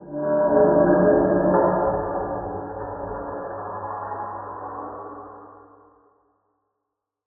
cave2.ogg